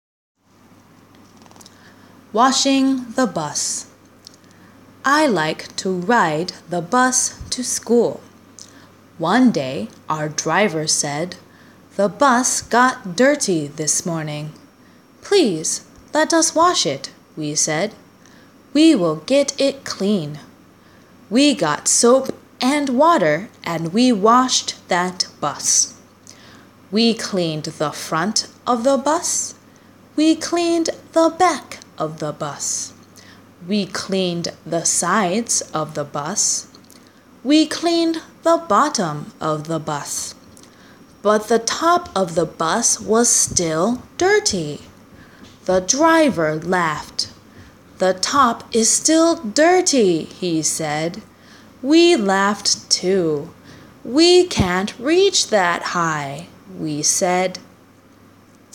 ■ゆっくり
Washing-the-Bus-slow-ver..m4a